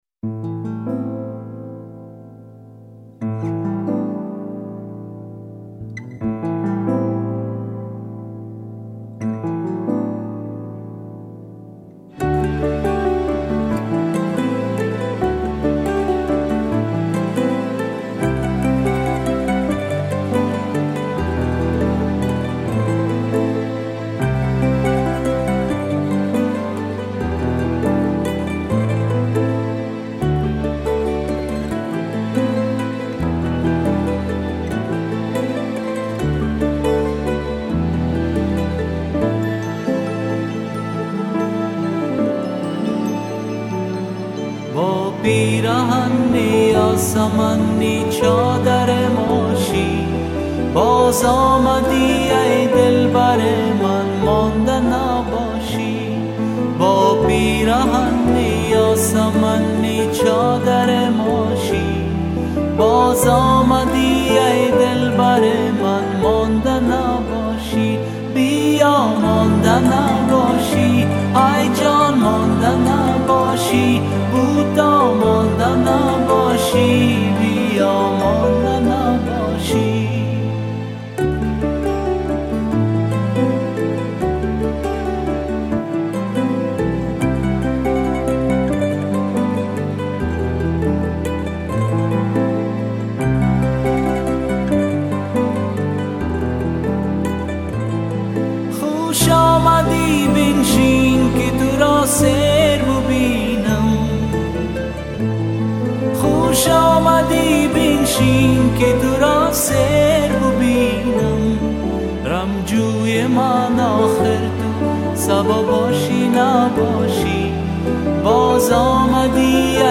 таджики